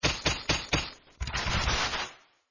hit_gong.mp3